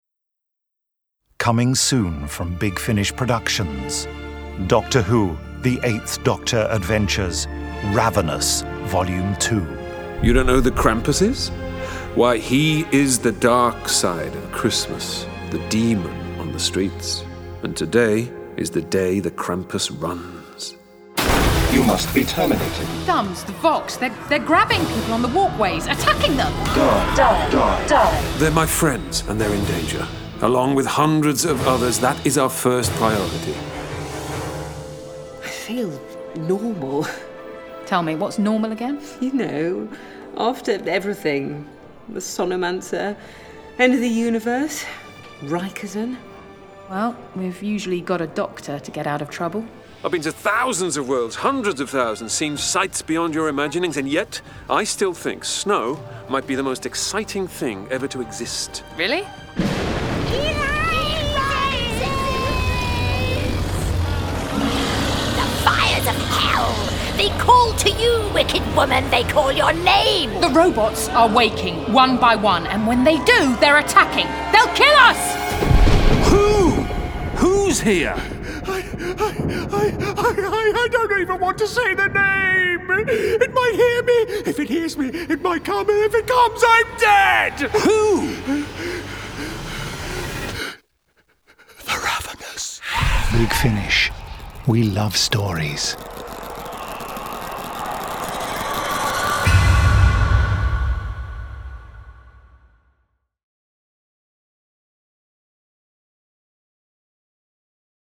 Starring Paul McGann Nicola Walker